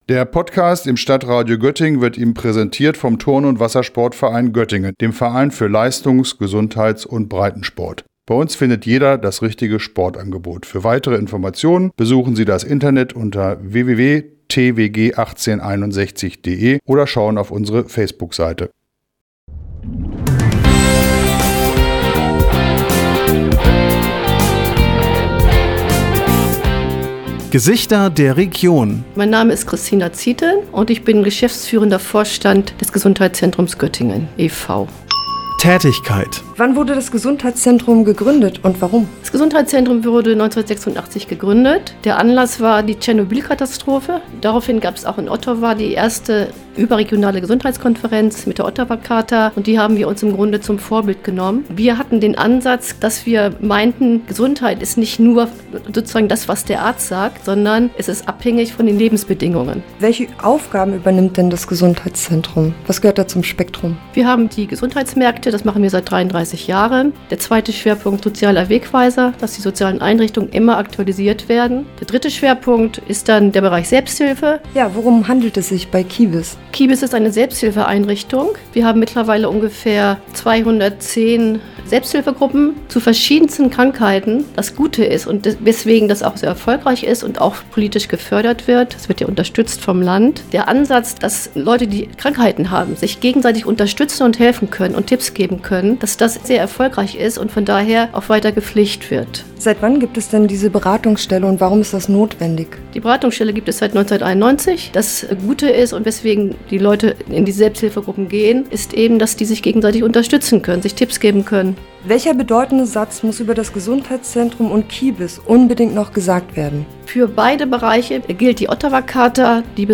Urheber: music by Michael Bertram, mb recording